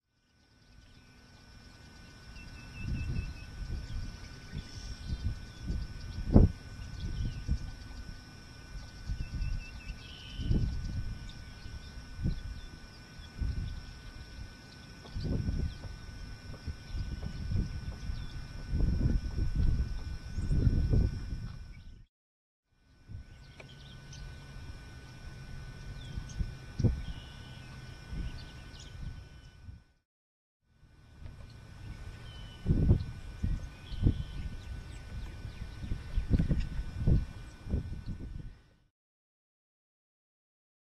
Eared Grebe  MOV  MP4  M4ViPOD  WMV